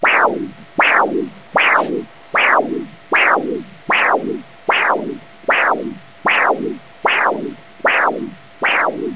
Duplex van de vaten
Naast het zichtbaar maken van de stroomsnelheid, is het ook mogelijk dit onderzoek te combineren met een doppler onderzoek. Behalve grafische presentatie, wordt dit signaal ook hoorbaar gemaakt.
duplex.wav